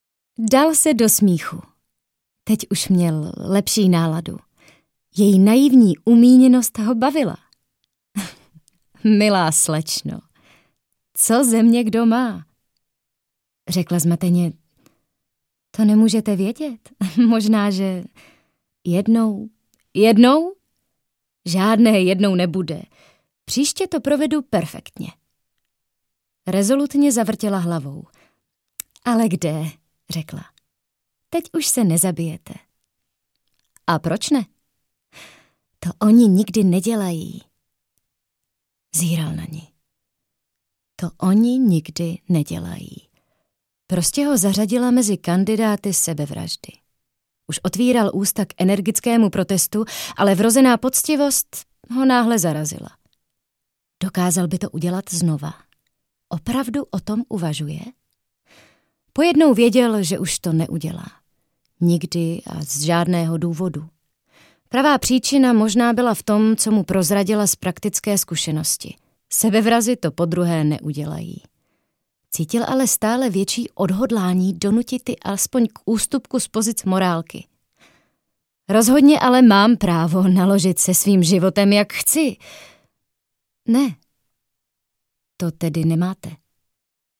Audio kniha: